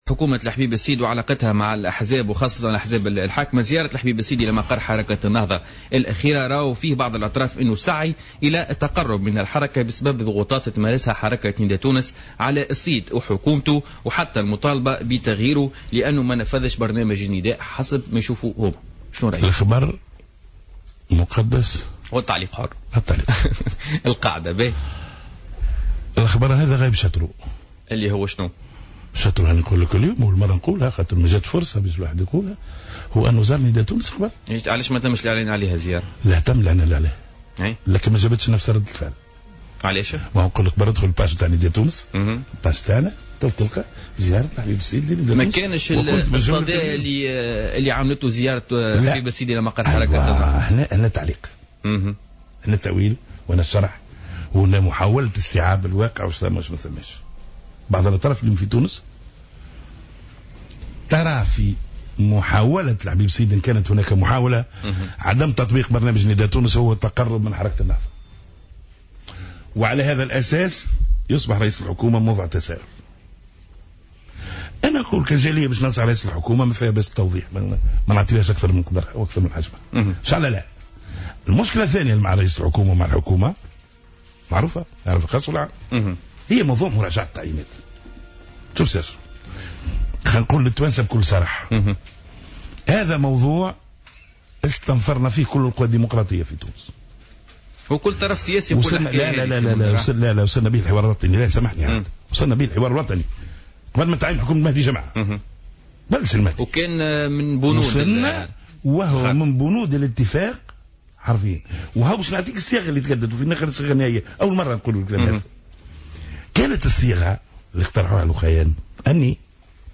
وأوضح في حوار مع "جوهرة أف أم" في برنامج "بوليتيكا"، أنه تم الإعلان عن هذه الزيارة في إبّانها إلا أن الاهتمام تركز على تلك التي تمت مع حركة النهضة لاحقا.